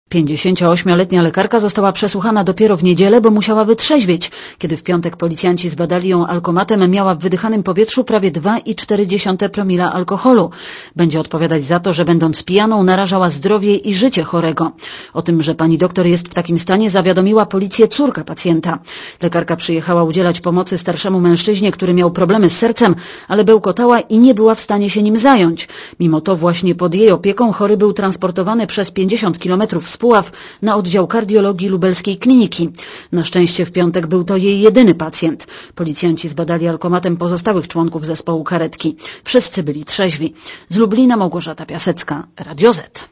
Źródło: Archiwum relacja reportera Radia ZET Oceń jakość naszego artykułu: Twoja opinia pozwala nam tworzyć lepsze treści.